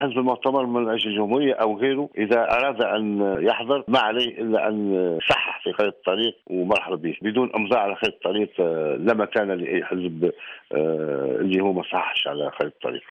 أكد الأمين العام المساعد للإتحاد العام التونسي للشغل بوعلي المباركي في تصريح لجوهرة اف ام اليوم الأحد 22 ديسمبر 2013 أن المؤتمر من أجل الجمهورية وغيره من الأحزاب التي لم تشارك من قبل في الحوار والتي تريد ان تلتحق بما تبقى من جلساته مطالبة اولا بالتوقيع على خارطة الطريق والالتزام بكل ماجاء فيها مشددا على انه لامجال لالتحاق أي حزب بالحوار دون التوقيع على خارطة الطريق.